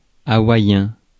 Ääntäminen
Ääntäminen France: IPA: [a.wa.jɛ̃] Haettu sana löytyi näillä lähdekielillä: ranska Käännös 1. hawaiano {m} Suku: m .